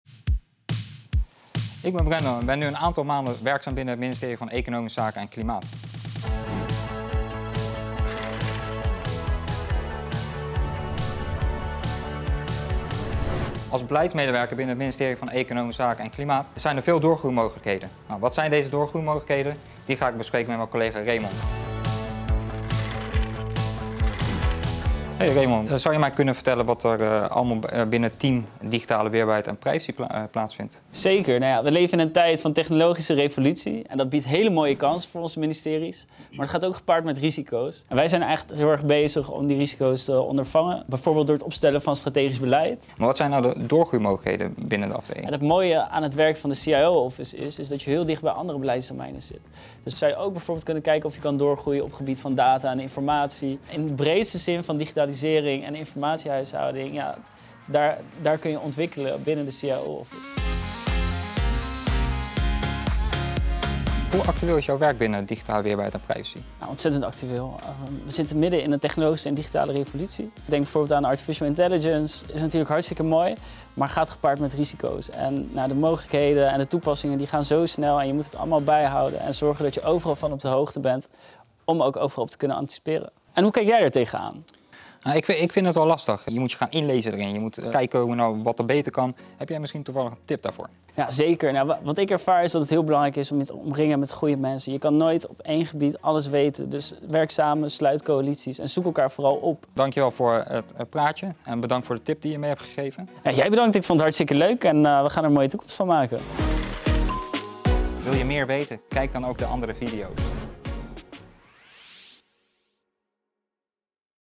In de videoserie Op Je Plek Bij gaan starters in gesprek met ervaren collega’s over het werken en de loopbaanmogelijkheden bij het ministerie van Economische Zaken (EZ, voorheen het ministerie van Economische Zaken en Klimaat).